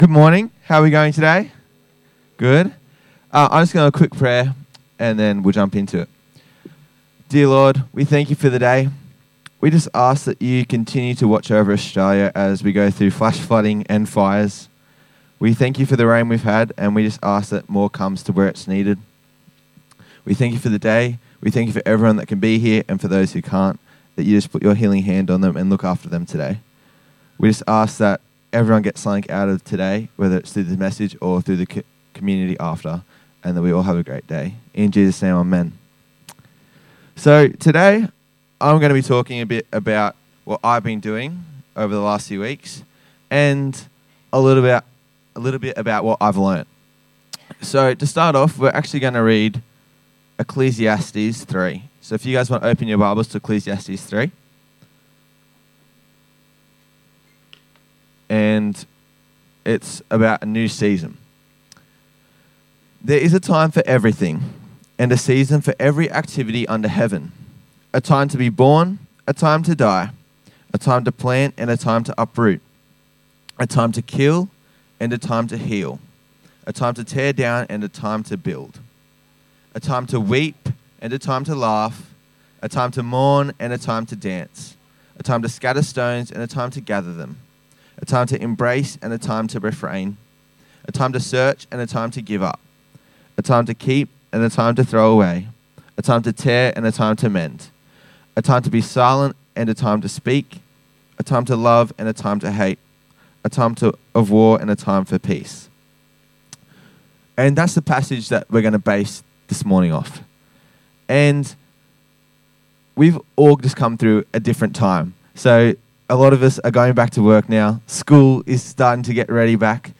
preached at the morning service.